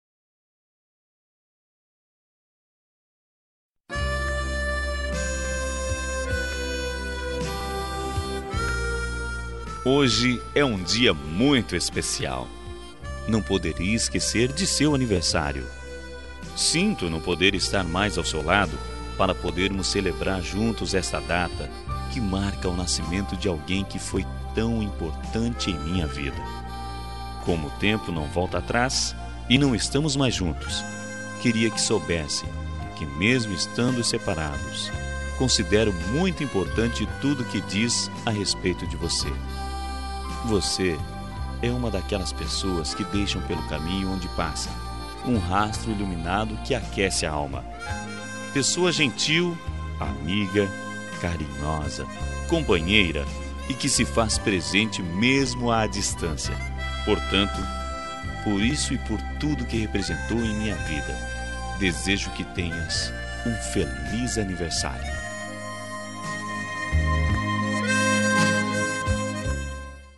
Telemensagem de Aniversário de Ex. – Voz Masculina – Cód: 1380